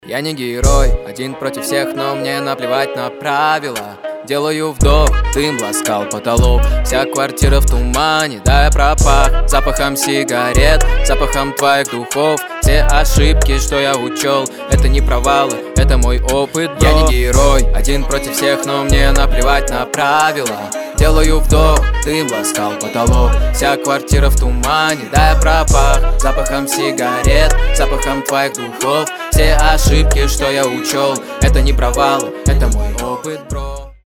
спокойные